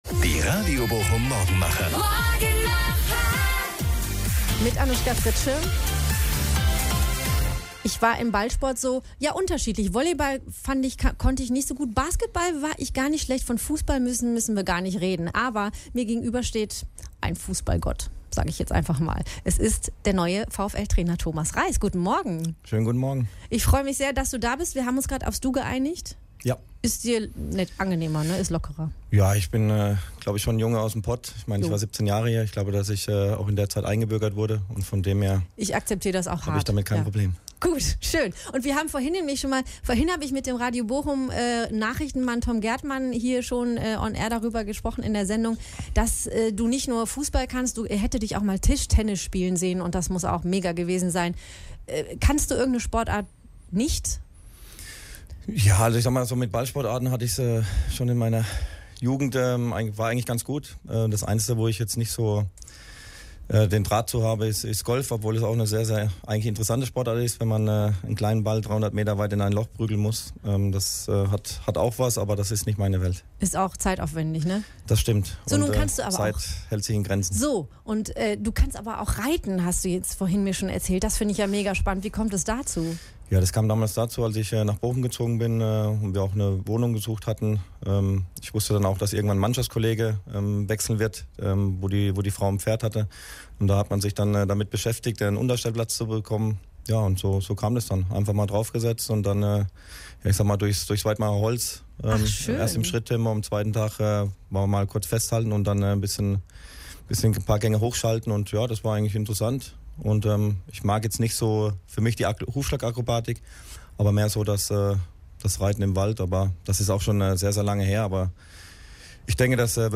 Neuer Trainer, neues Glück - darauf hofft der VfL, auf Erfolg mit Thomas Reis! Wir haben ihn frisch angestellt zum Interview eingeladen.